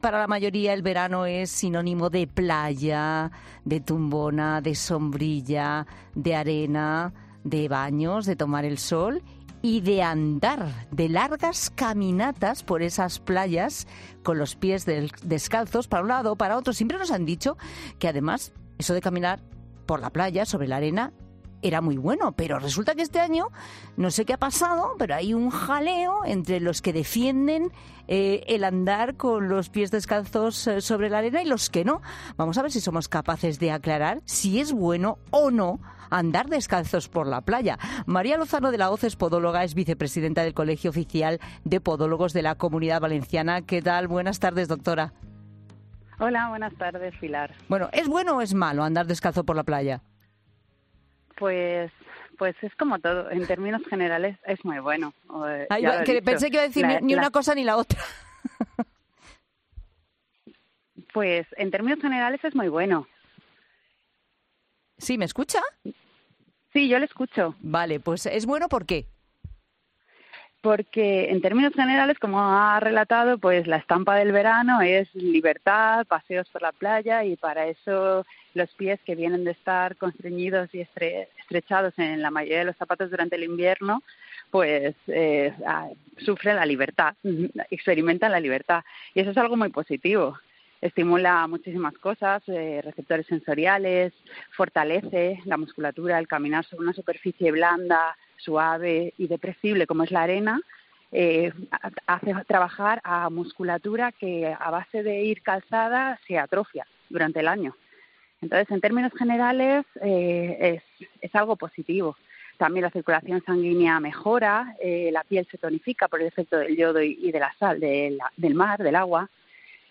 ¿Es bueno para los pies pasear descalzo por la arena de la playa? Una podóloga zanja el debate